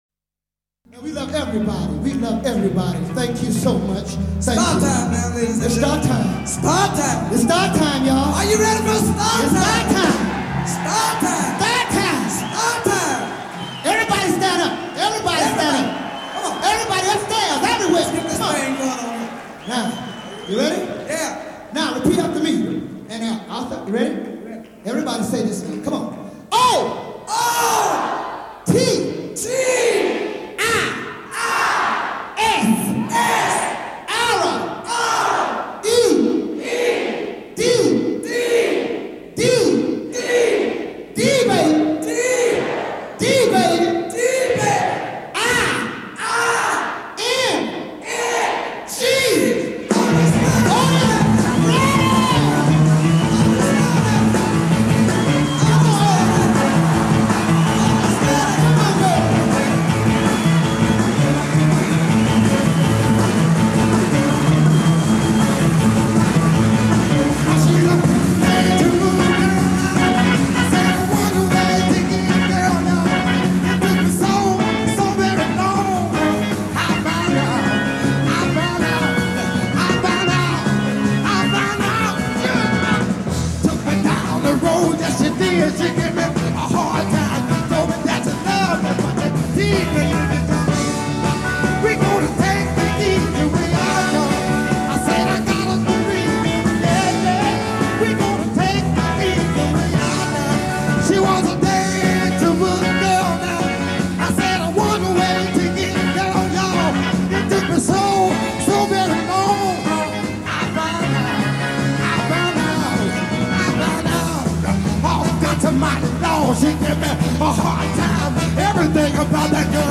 in concert from Stockholm 1967
Soul Legends in concert.